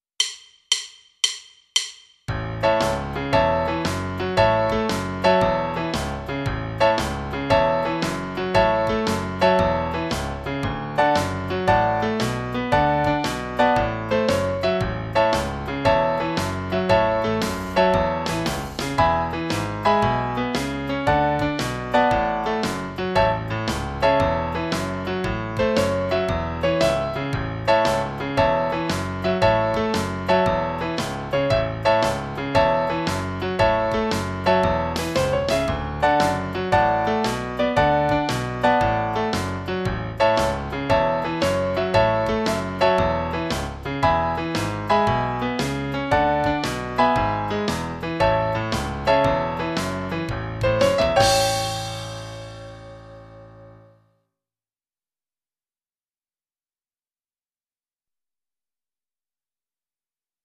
Een liedje!